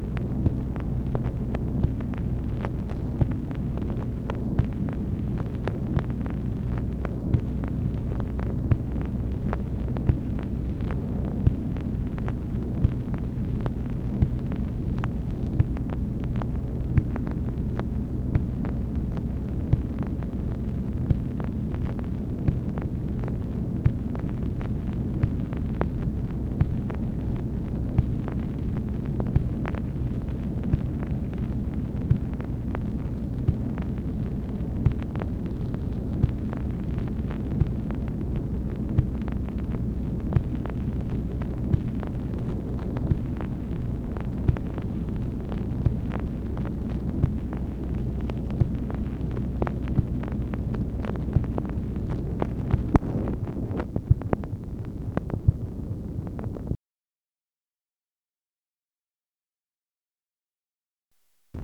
MACHINE NOISE, November 23, 1964
Secret White House Tapes | Lyndon B. Johnson Presidency